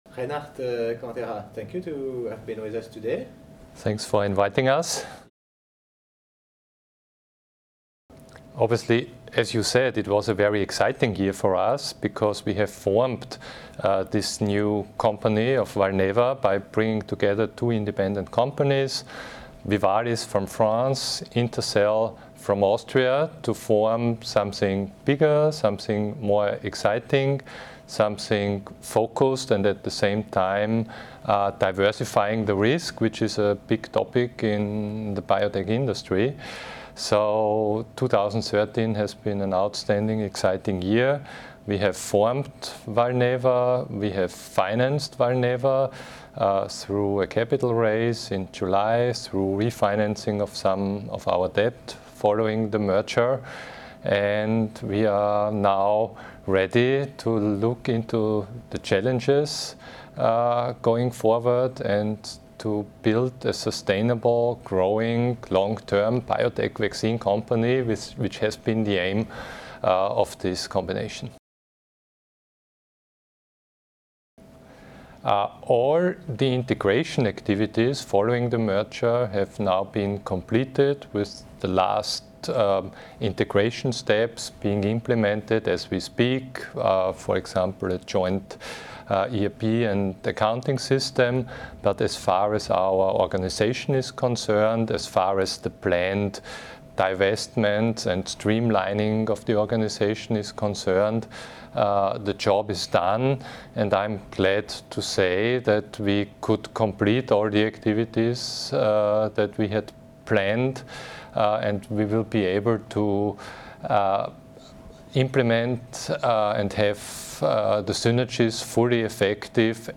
Oddo Midcap Forum 2014 : Strategy and target for Valneva (in English)